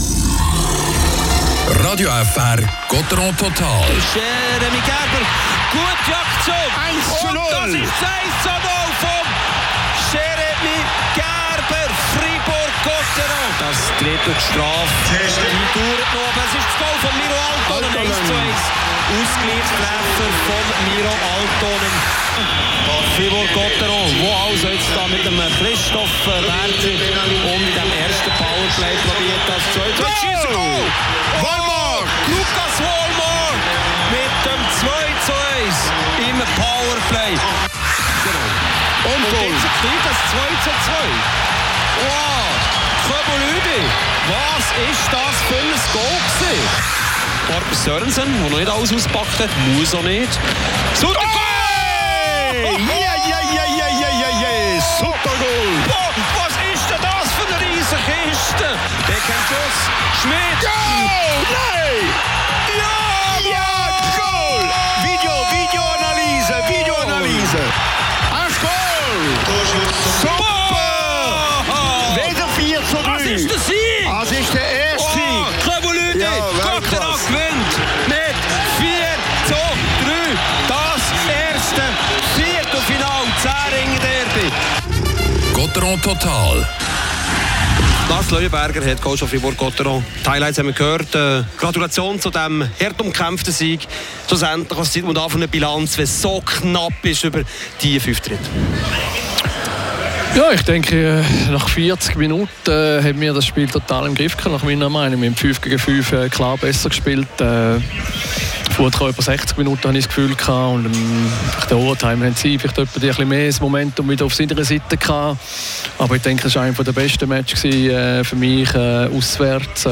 Beschreibung vor 1 Jahr Die Drachen bezwingen den SC Bern mit 4:3 nach doppelter Overtime — der erste von vier nötigen Siegen ist geschafft. Hier gibt’s die Spielerinterviews